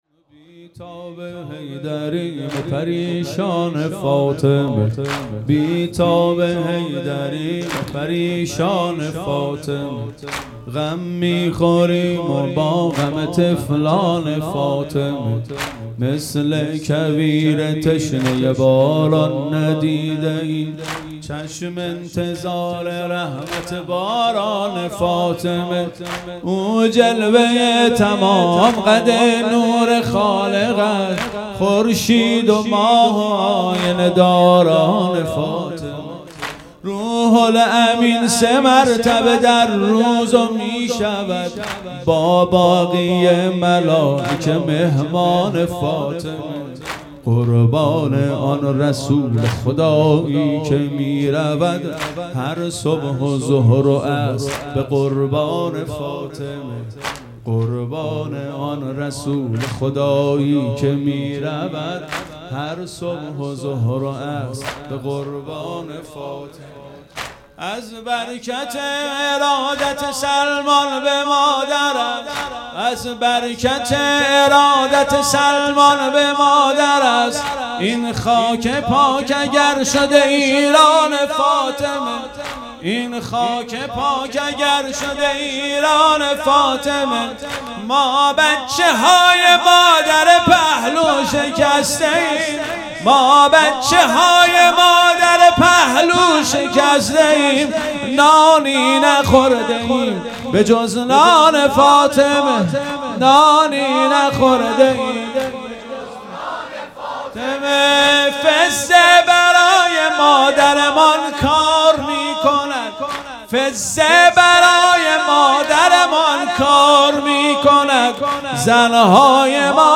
هیئت مکتب الزهرا(س)دارالعباده یزد
0 0 واحد تند